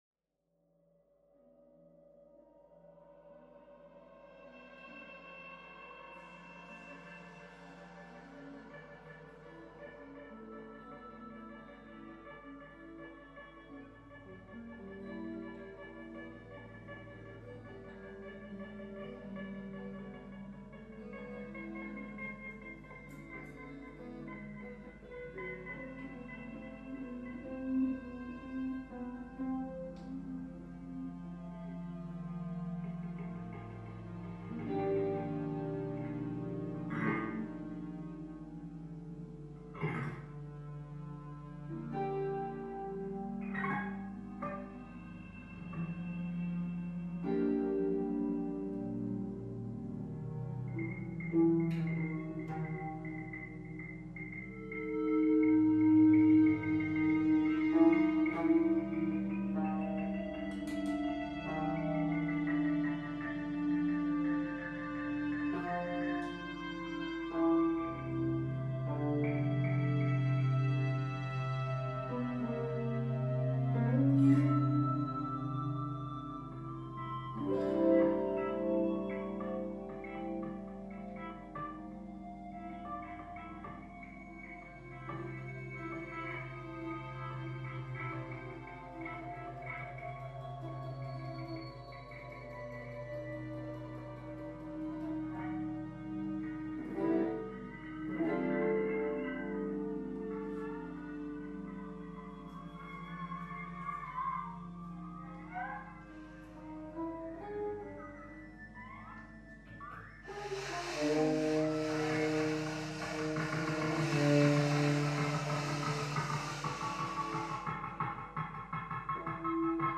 Saxophon/Samples
E-Gitarre